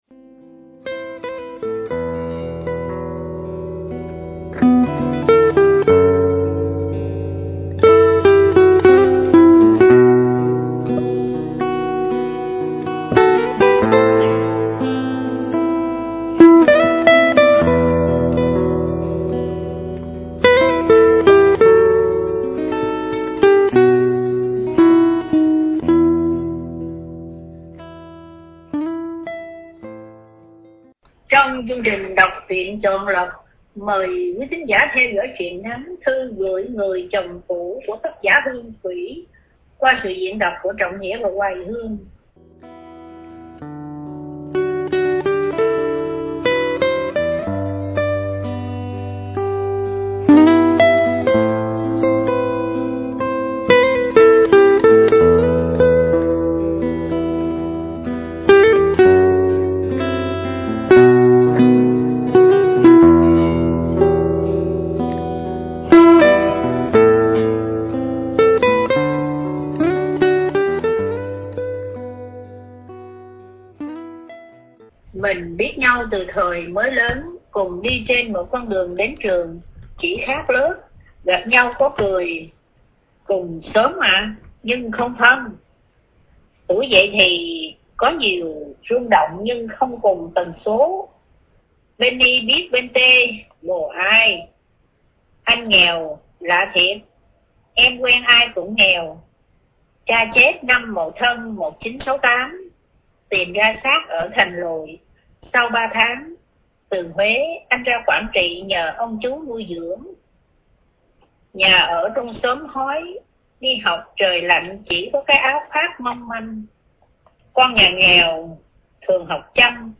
Đọc Truyện Chọn Lọc